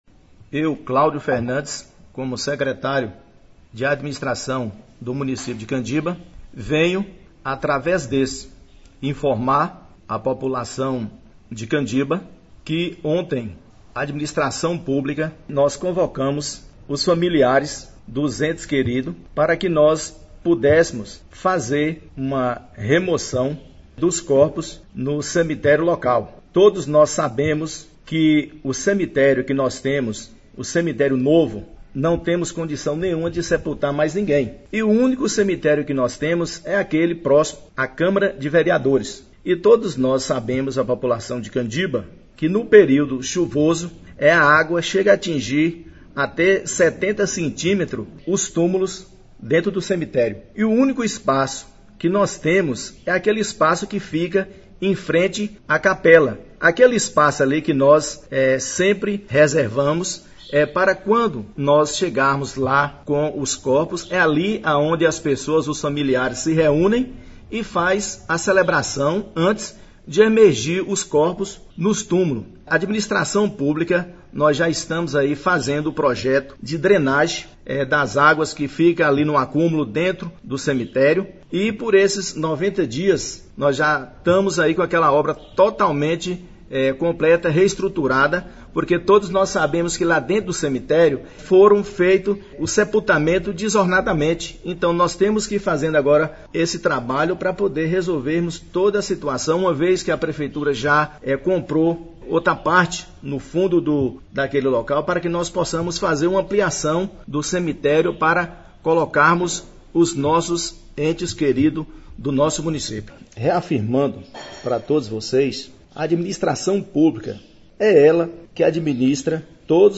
🎙ÁUDIO – Secretário de Administração de Candiba, Claudio Fernandes esclarece ação realizada no Cemitério de Candiba, que contou com autorização de familiares, visando ampliar e melhorar o espaço público.